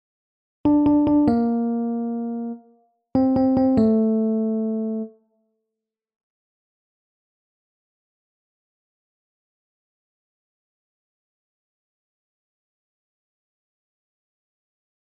Grote terts